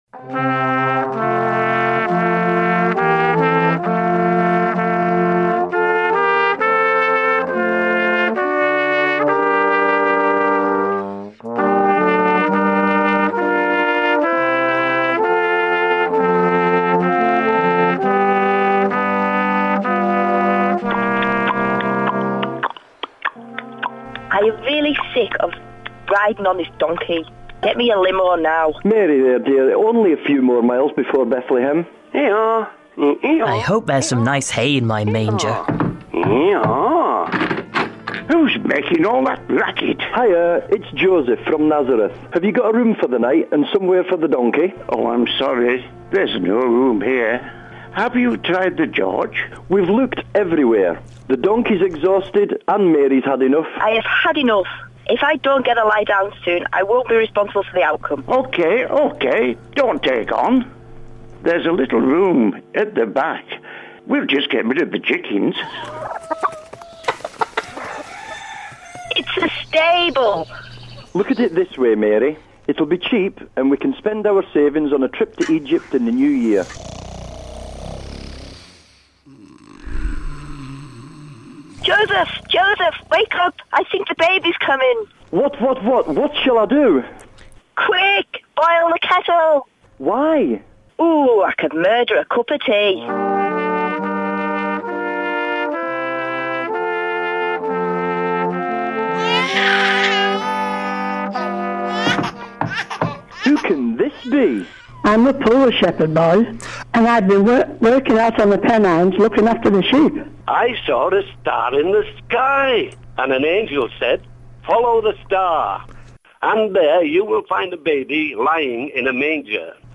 He asked the listeners to get in touch to play the roles of Mary, Joesph, the wise men, shepherds, The Angel Gabriel, the inn keeper, and the donkey.